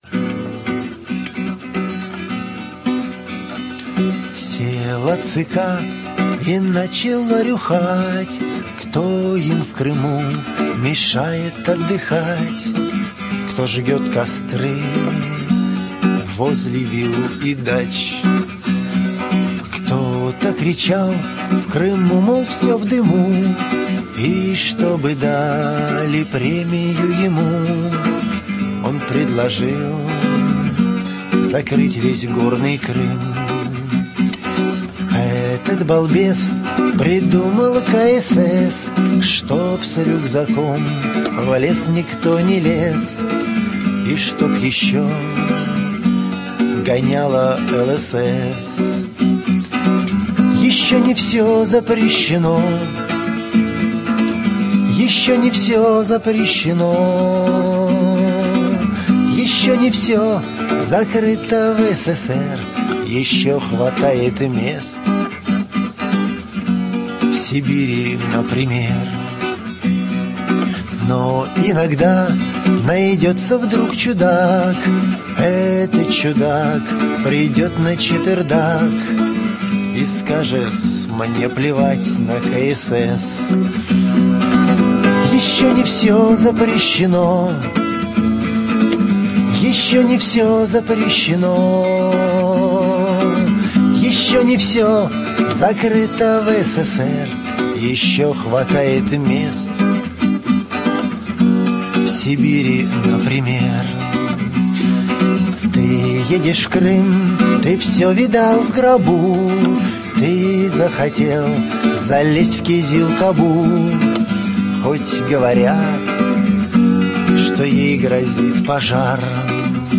Шуточная песня